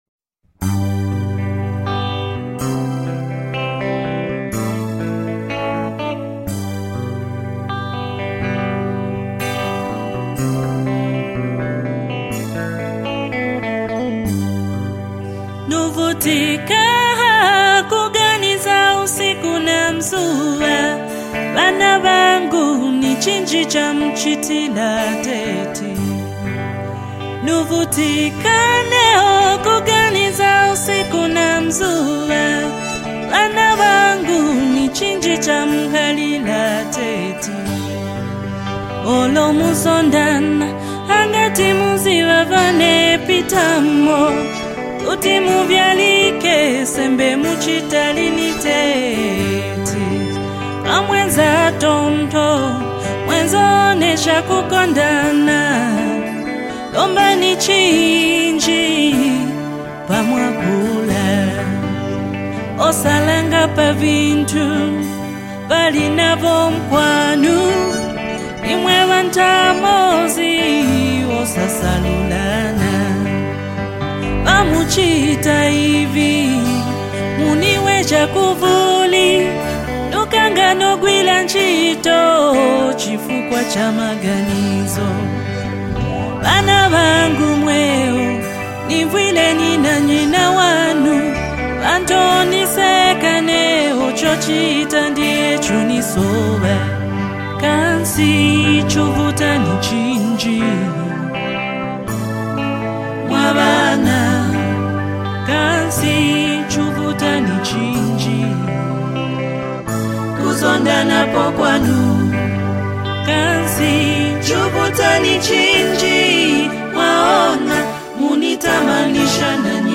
soulful and heartfelt